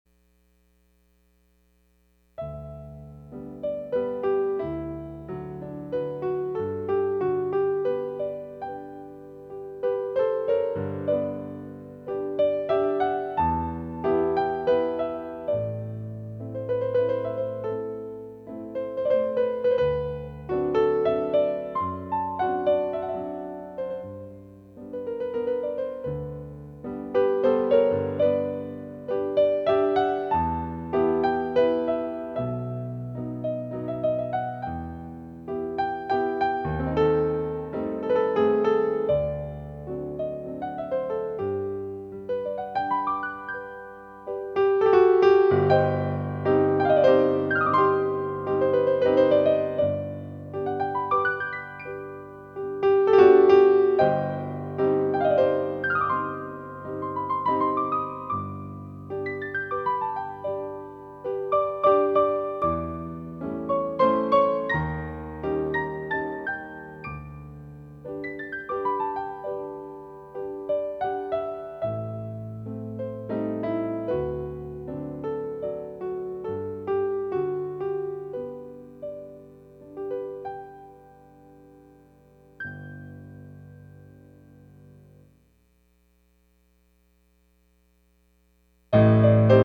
Music for Adagio